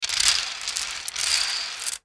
Звуки счётной машинки
Крутим кругляшки на счетах